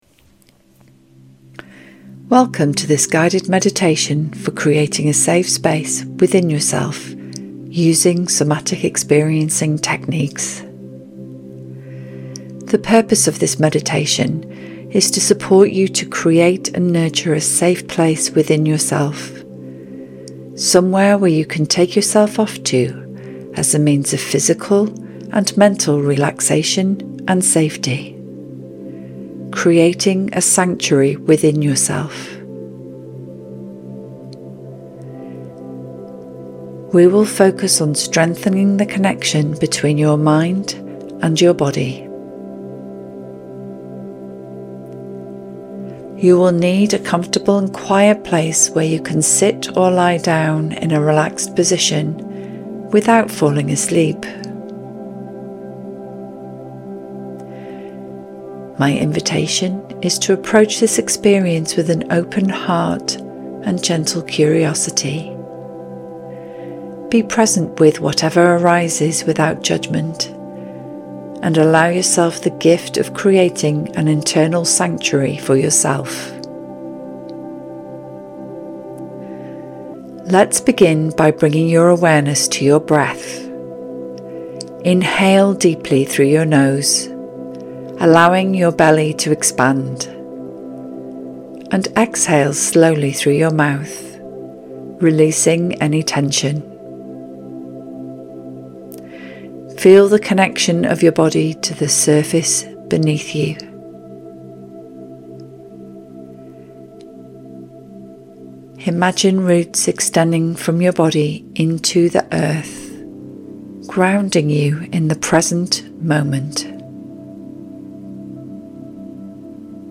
Welcome to this guided meditation for creating a safe inner space using Somatic Experiencing techniques. In around 10–15 minutes, you’ll be guided through grounding practices, a body scan, and a visualisation to help you imagine and nurture your own sanctuary within—a place of calm and safety you can return to anytime.